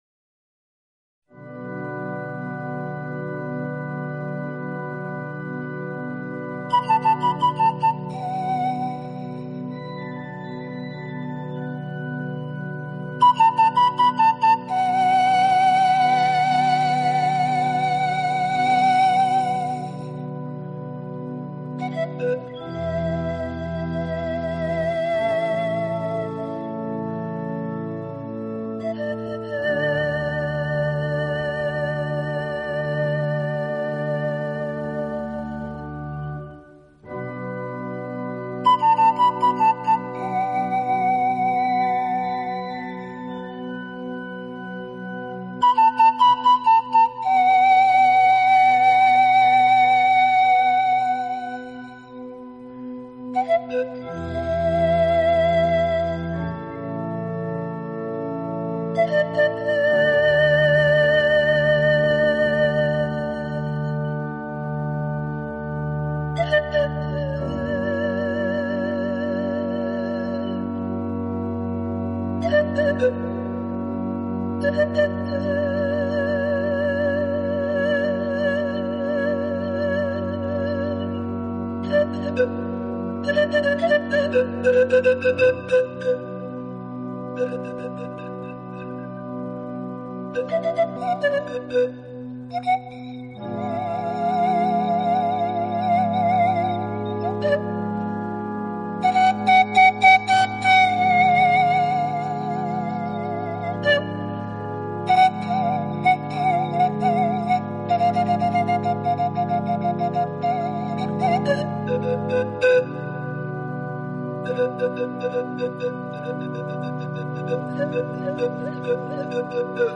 【排箫专辑】
Genre................: Instrumental